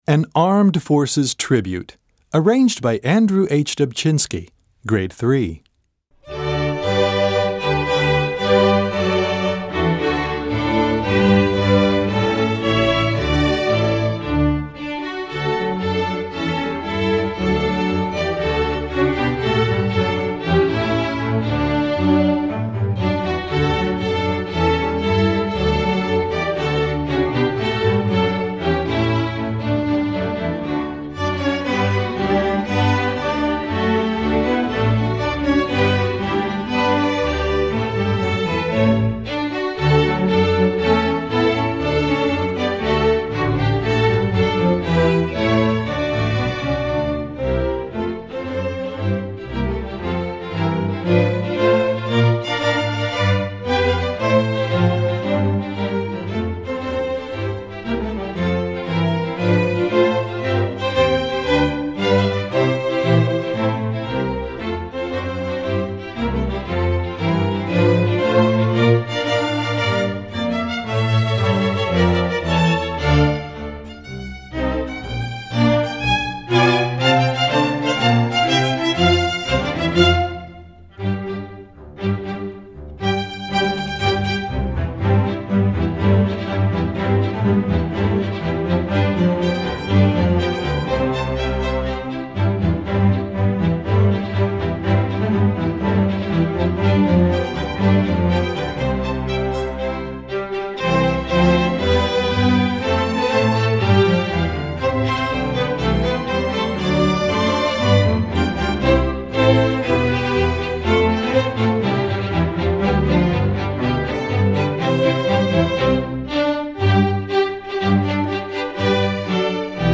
Gattung: Streichorchester
Besetzung: Streichorchester